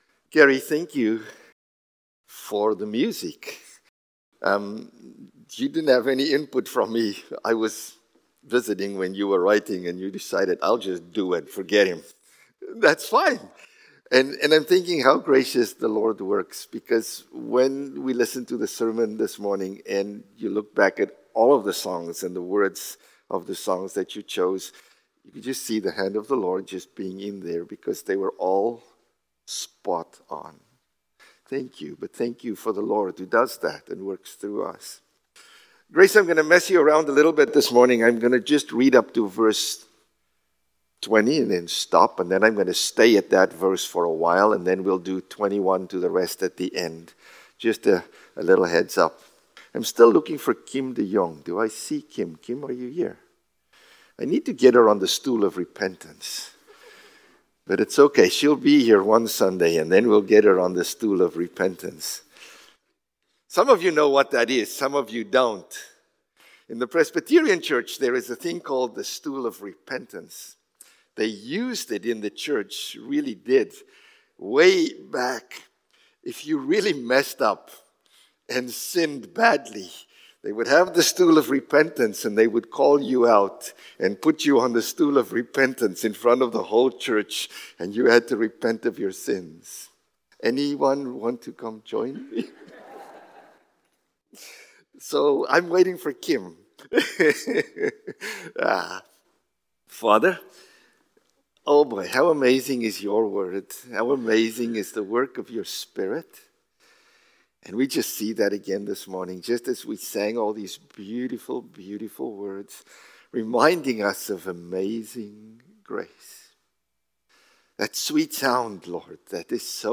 March-2-Sermon.mp3